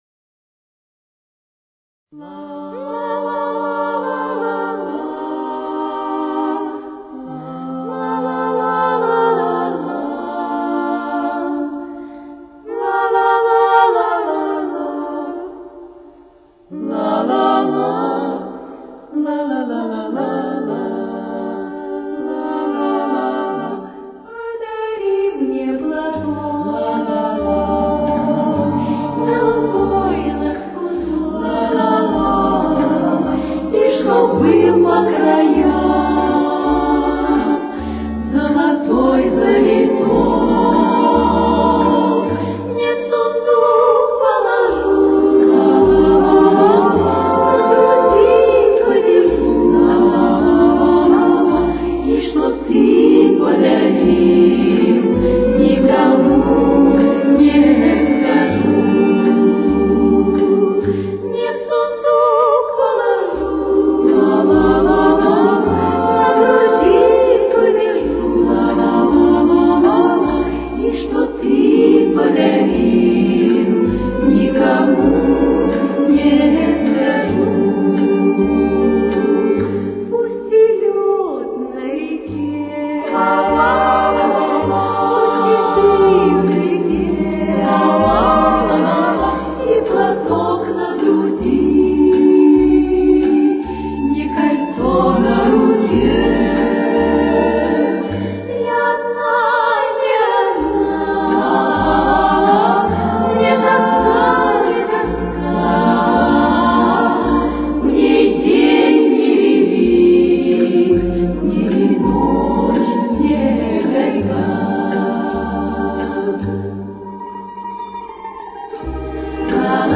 Темп: 48.